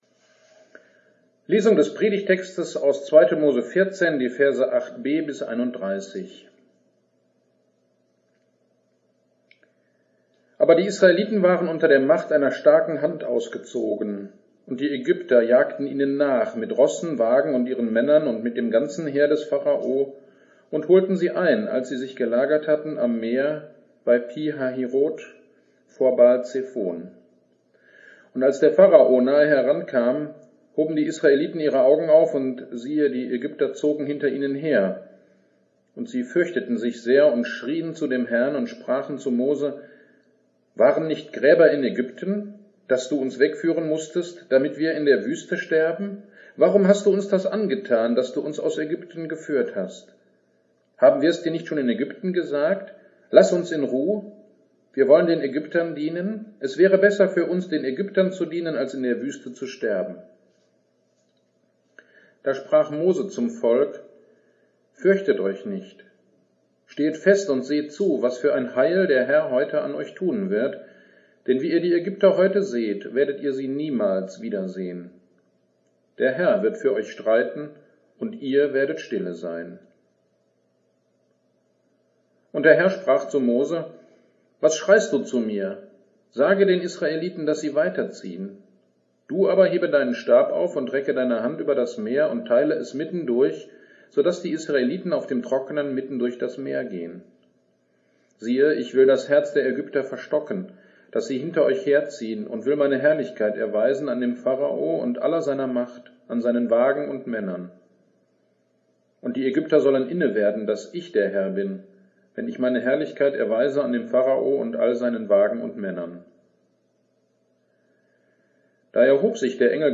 Predigt 2. Moses 14